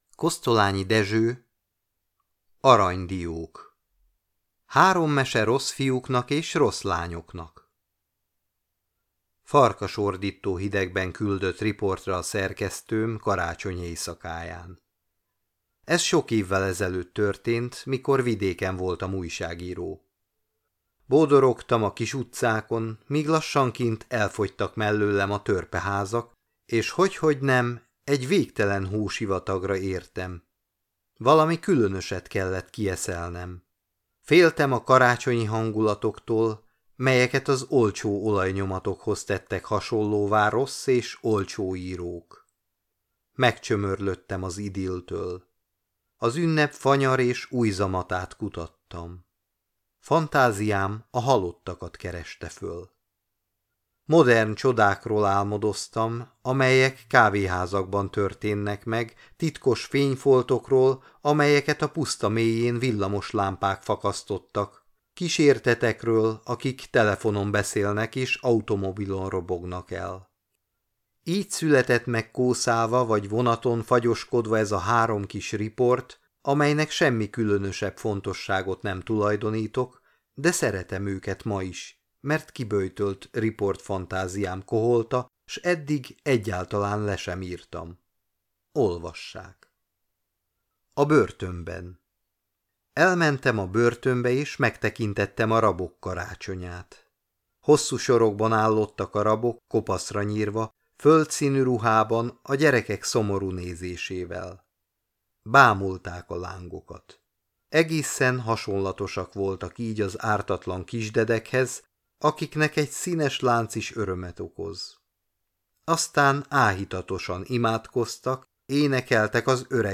Mondott történetek – Kosztolányi Dezső: Arany diók